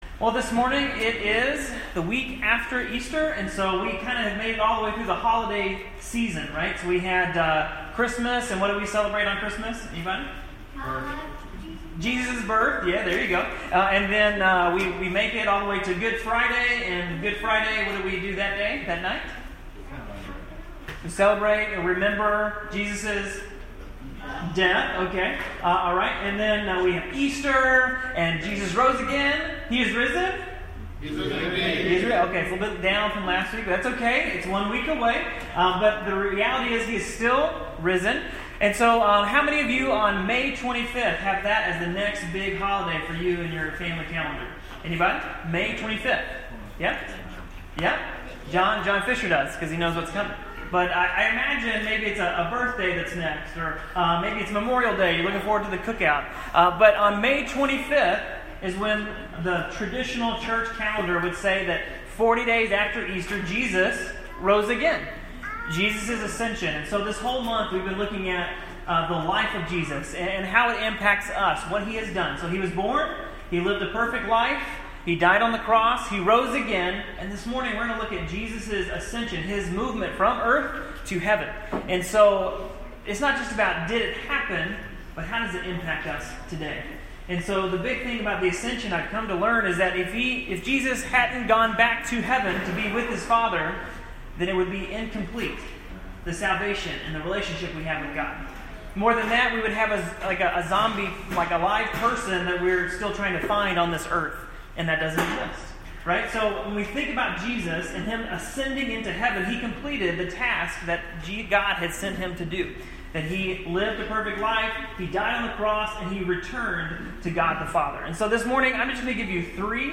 Easter Sermon Series - Sojourn Church